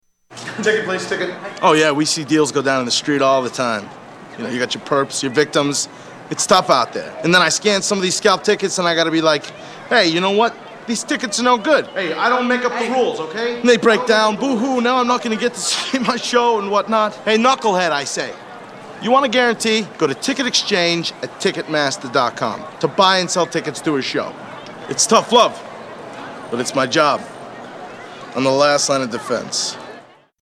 A Ticketmaster Commercial